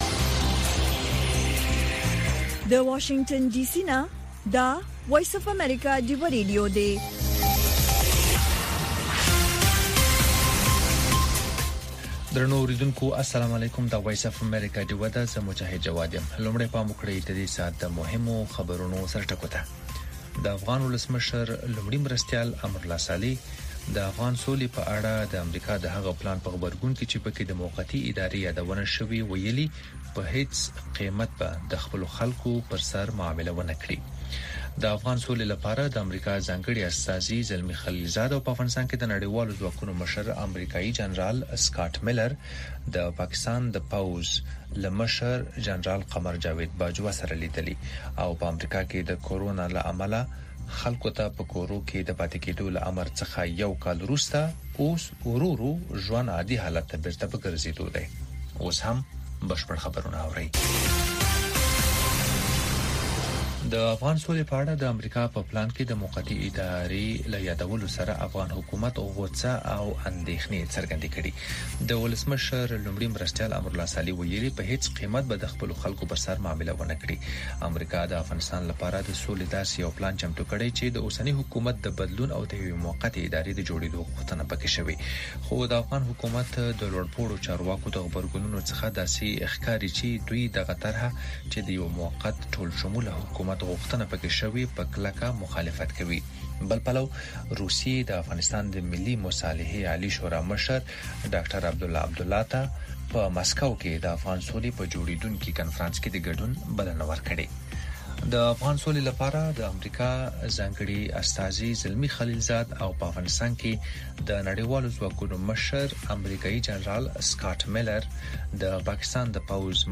خبرونه